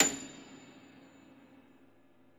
53c-pno29-A6.wav